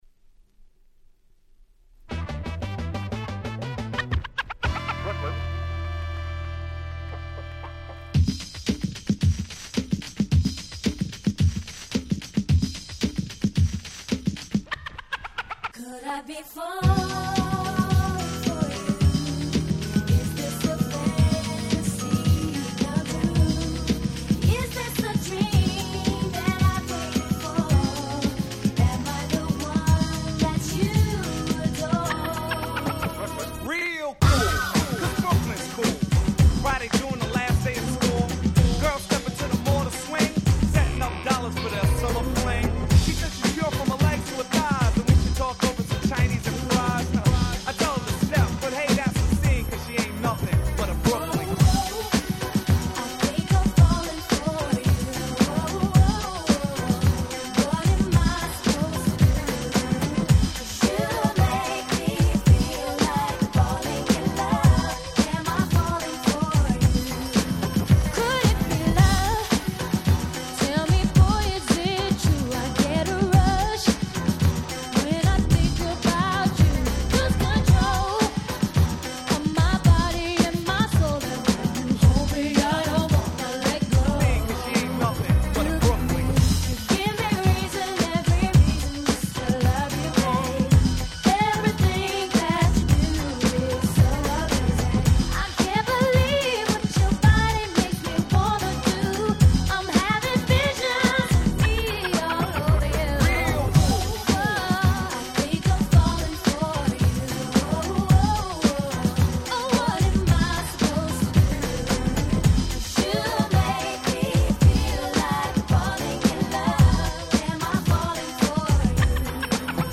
Party感3割増しでよりフロア仕様に！